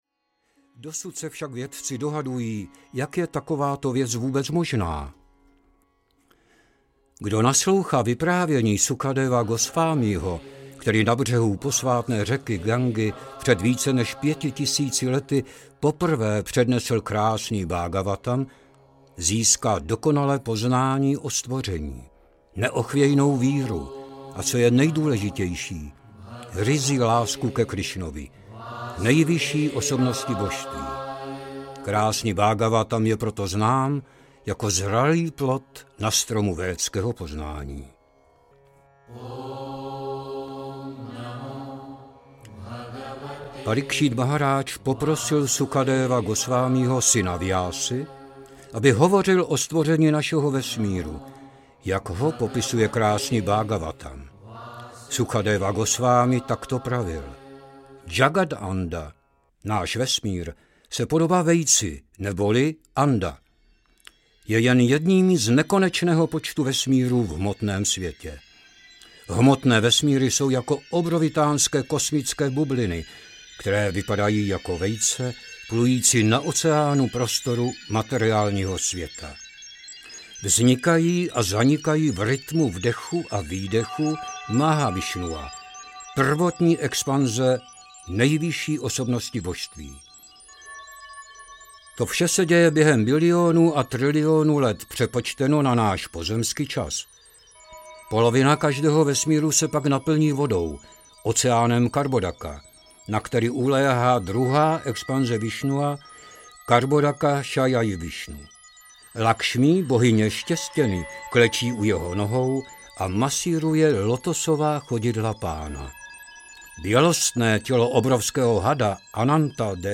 Krásný Bhágavatam díl 2. audiokniha